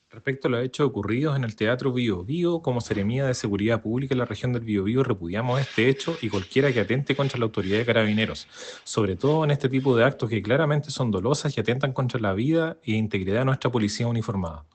Ante ello, el seremi de Seguridad en calidad de interino en el Bío Bío, Carlos Uslar, lamentó y condenó el hecho.